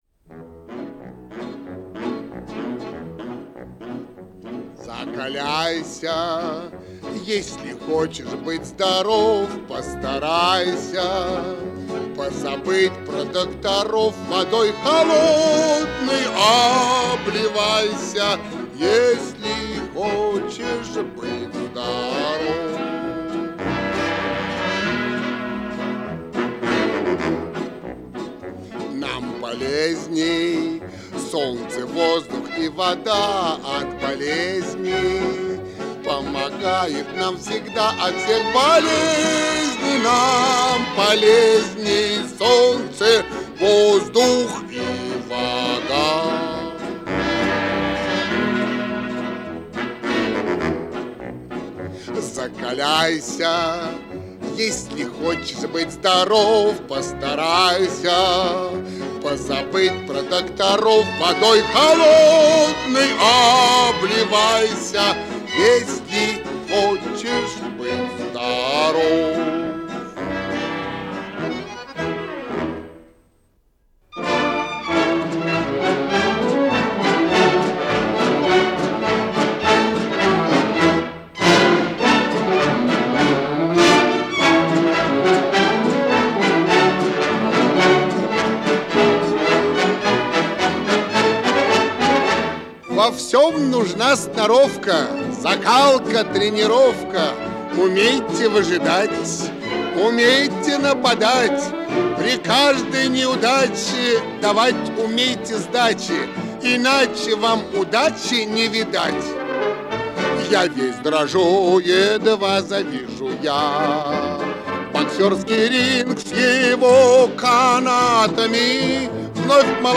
марш